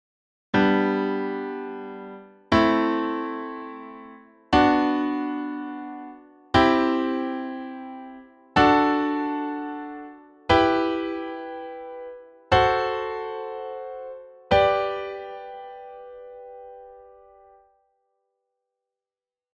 These chords all follow the key signature of G major (in this case, F#).
G major scale chords ascending
Chords-in-G-Major.mp3